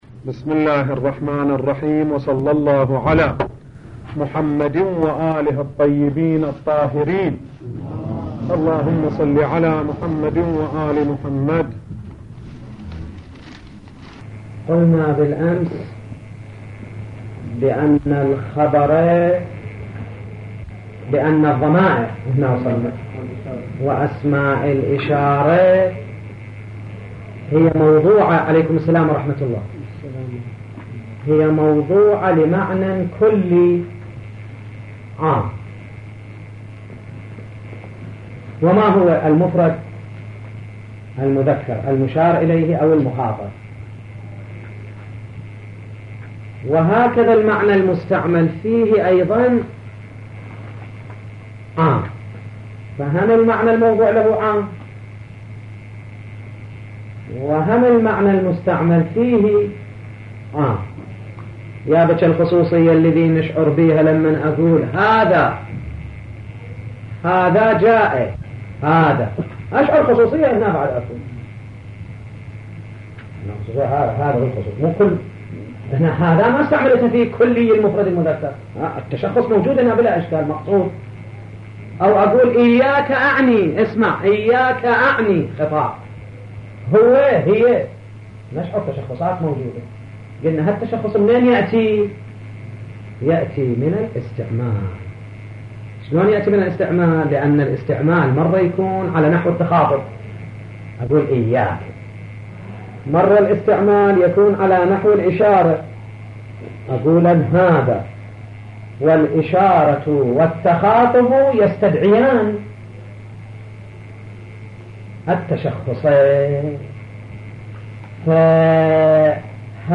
مرجع دانلود دروس صوتی حوزه علمیه دفتر تبلیغات اسلامی قم- بیان